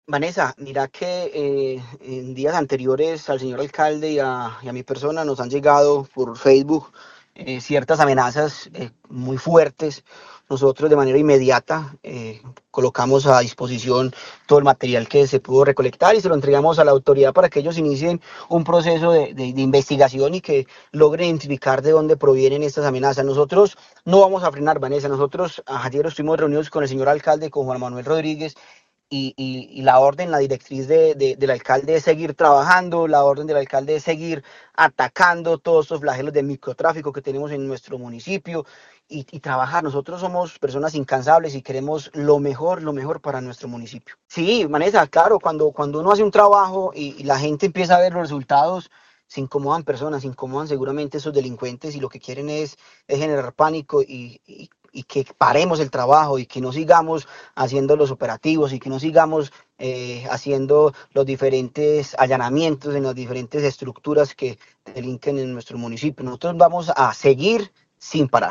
Secretario de Gobierno de Quimbaya